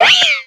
Cri de Mistigrix dans Pokémon X et Y.